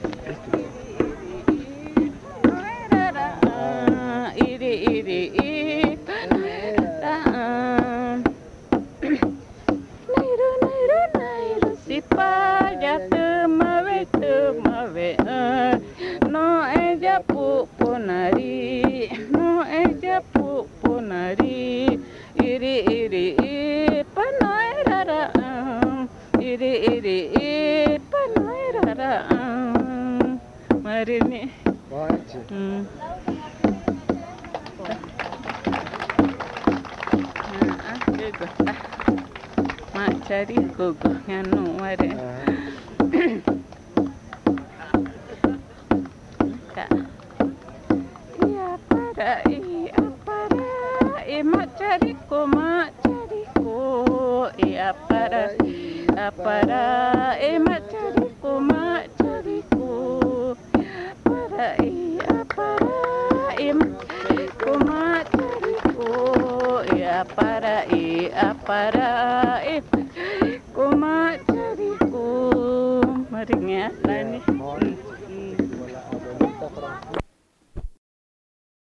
San Juan del Socó, río Loretoyacu, Amazonas (Colombia)
La abuela (anónima) canta sobre la abuela Pupunari y el Macharico.
The (anonymous) elder woman sings about the elder woman Pupunari and the Macharico.